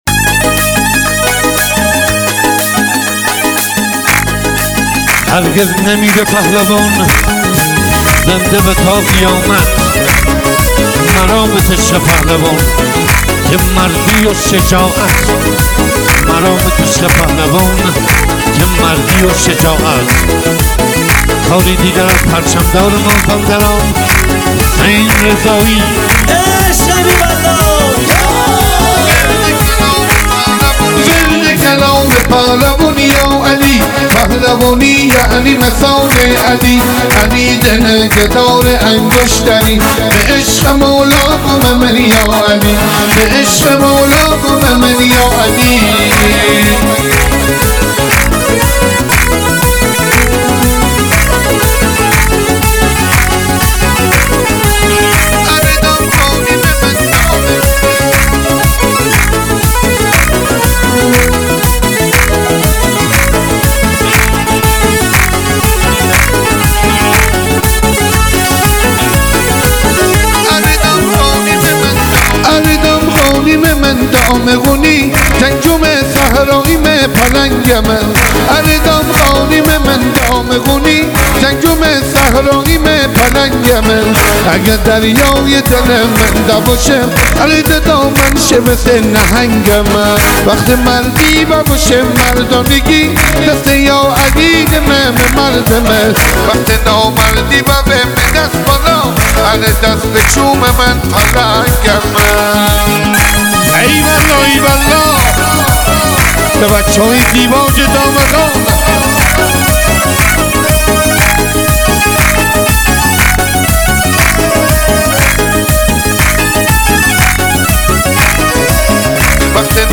با حال‌وهوای محلی و پرانرژی